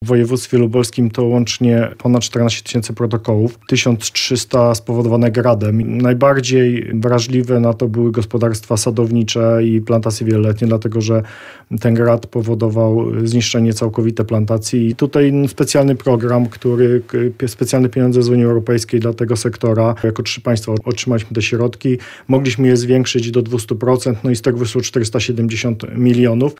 - Umowa o wolnym handlu z krajami Ameryki Południowej nie będzie korzystna dla polskich rolników - powiedział w porannej rozmowie Radia Lublin wicewojewoda